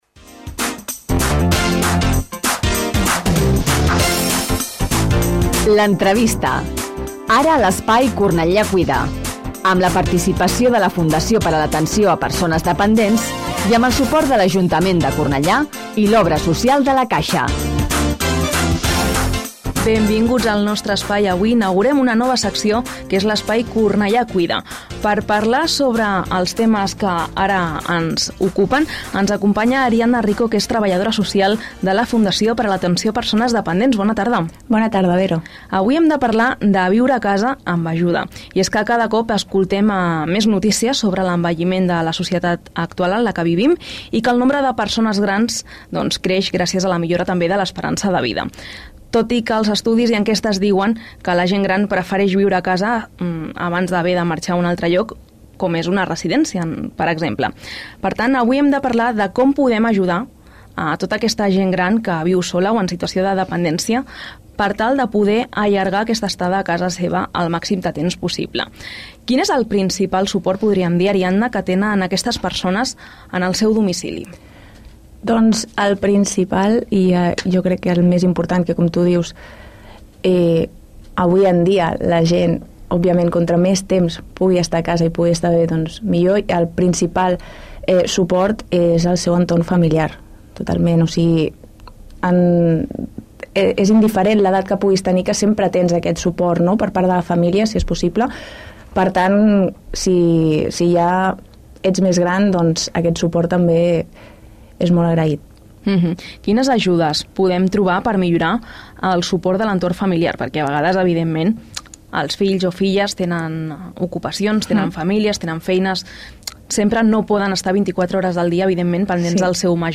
Anar al programa L’Entrevista és un espai de reflexió i actualitat diari, de dilluns a divendres a les 13:30h.